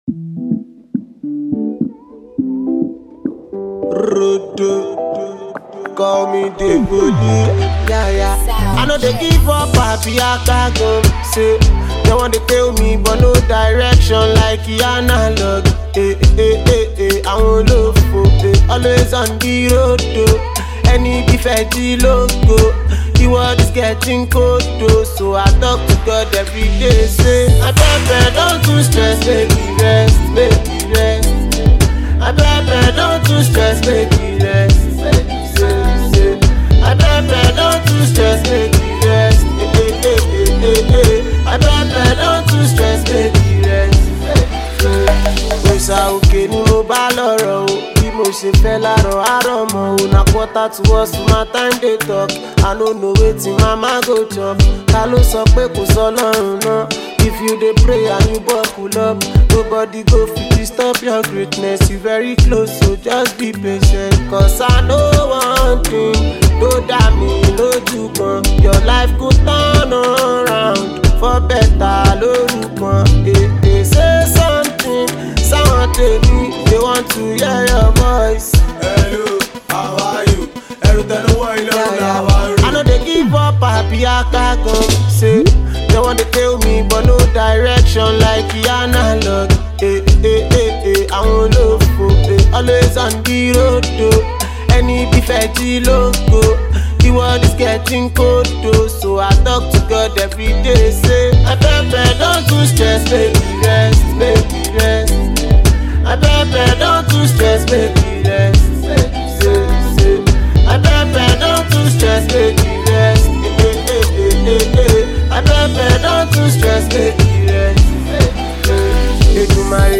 Nigerian talented rapper
catchy new single